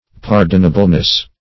Search Result for " pardonableness" : The Collaborative International Dictionary of English v.0.48: Pardonableness \Par"don*a*ble*ness\, n. The quality or state of being pardonable; as, the pardonableness of sin.
pardonableness.mp3